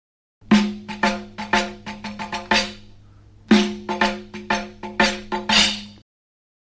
It either goes with Six beats
05 3Samaii 6 Rhythm.mp3